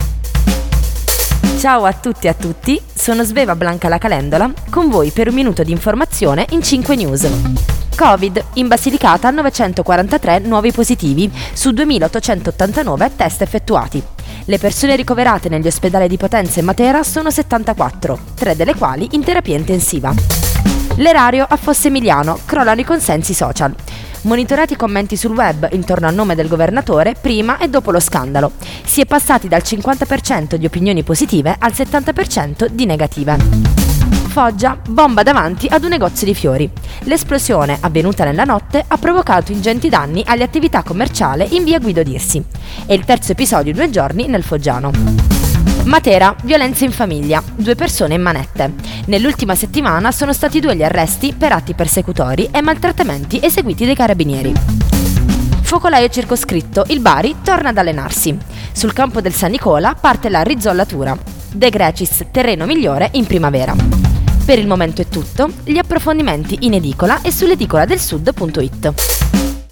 Giornale radio alle ore 19.